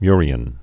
(myrē-ĭn, myrēn)